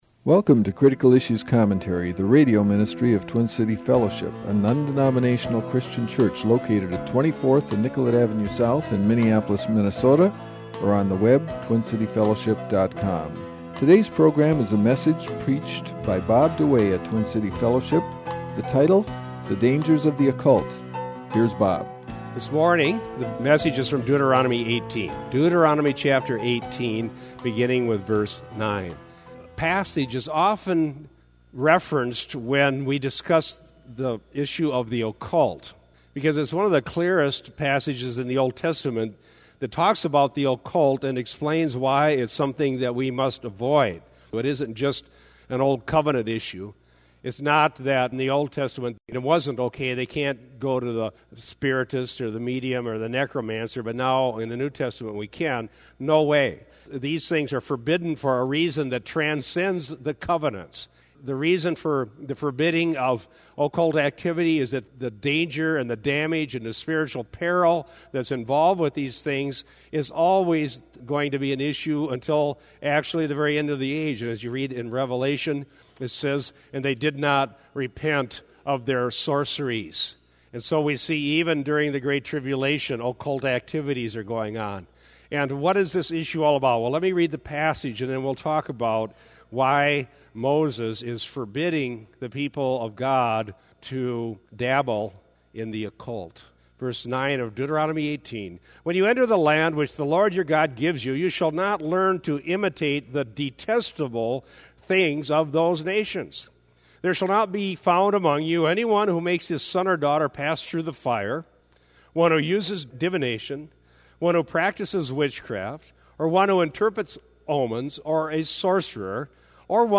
For a sermon on the forbidden nature of occult knowledge click here.